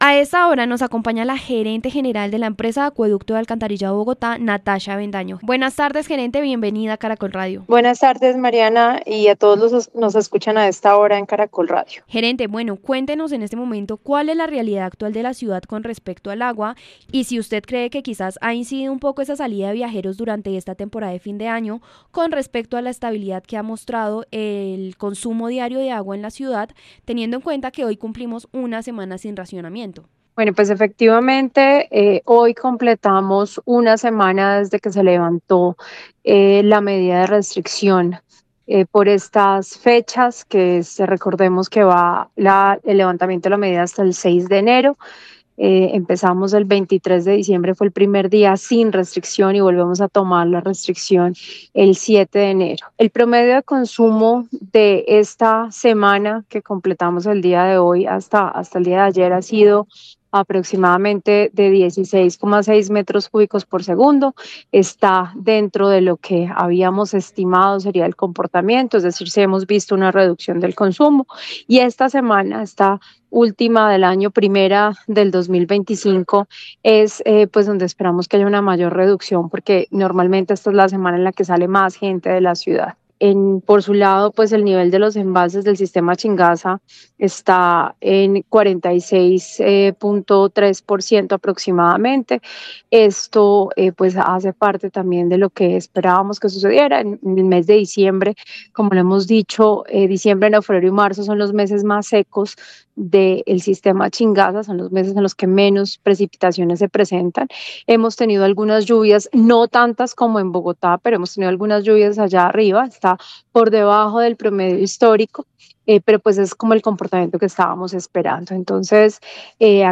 En Caracol Radio hablamos con Natasha Avendaño, Gerente General del Acueducto de Bogotá, quien nos explicó el comportamiento del consumo de agua durante este fin de año y el pronóstico para el próximo año.